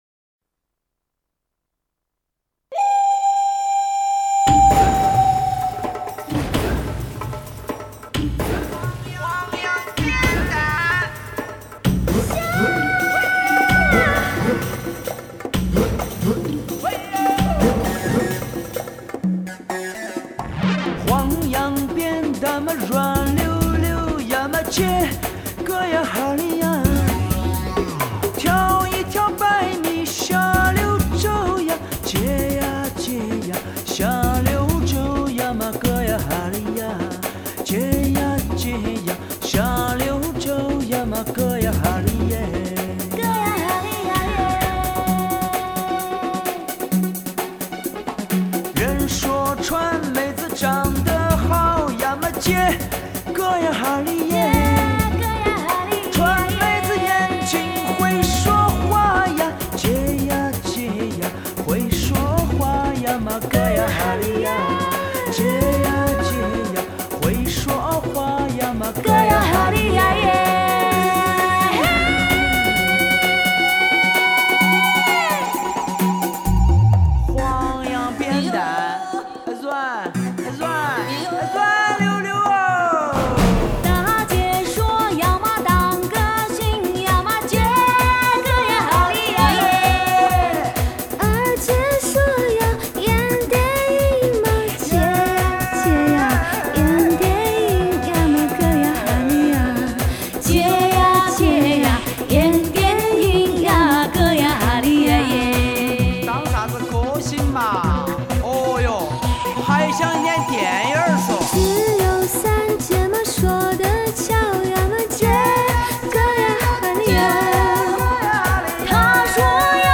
风趣欢快的重庆民歌
重庆秀山民歌